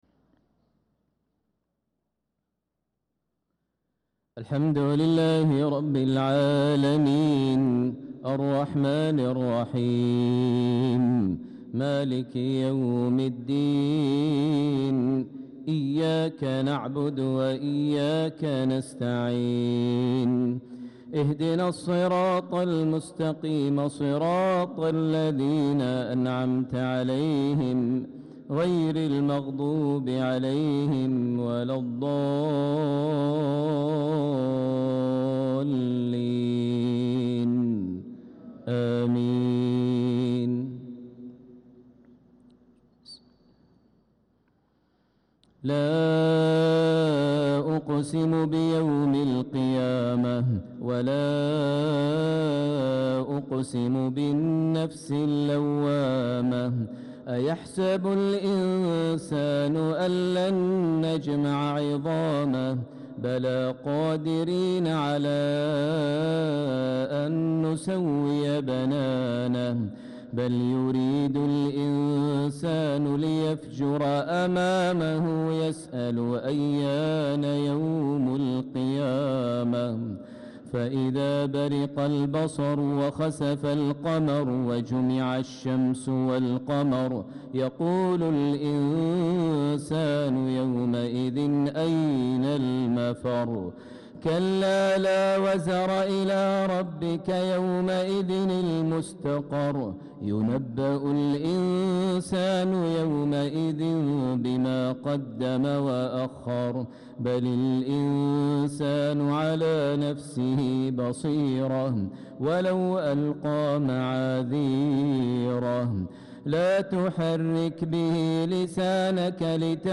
صلاة العشاء للقارئ ماهر المعيقلي 19 ذو الحجة 1445 هـ
تِلَاوَات الْحَرَمَيْن .